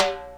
Timbale.wav